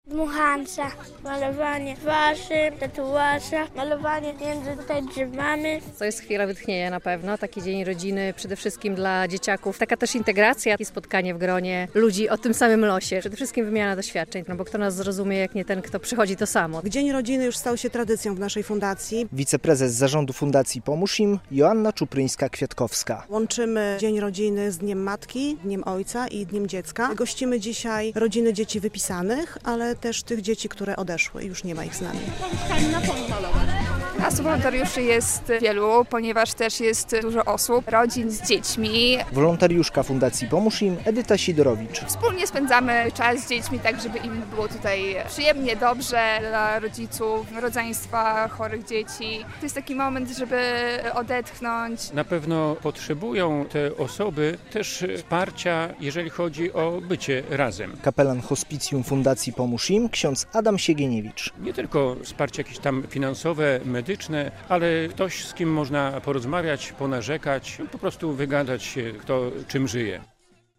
Piknik Fundacji "Pomóż Im" w Sochoniach - relacja